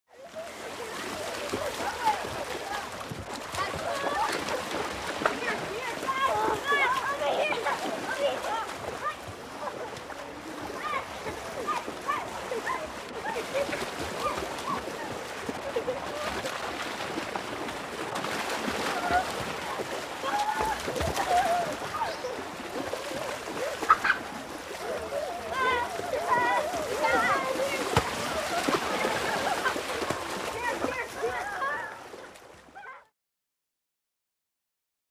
Kids Swimming | Sneak On The Lot
Swimming Pool Ambience With Children Splashing, screaming, And Playing